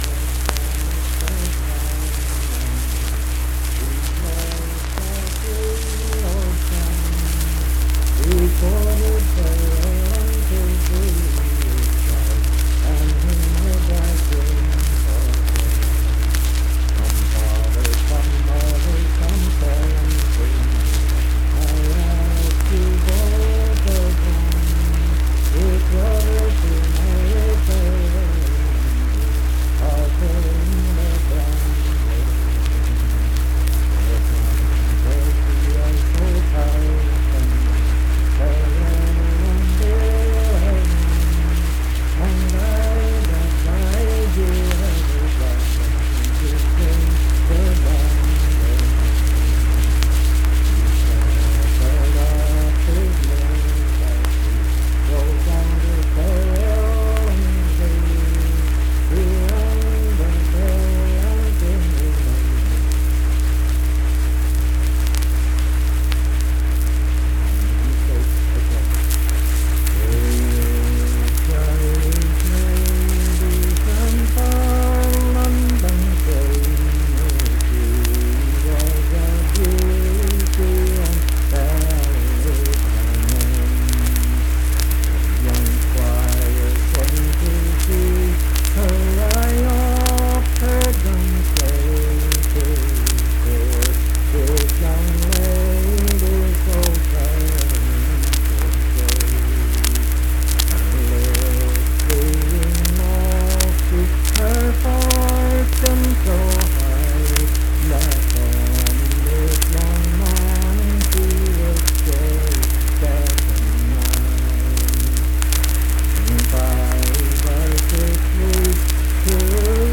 Unaccompanied vocal music
Gauley Mills, Webster County, WV
Voice (sung)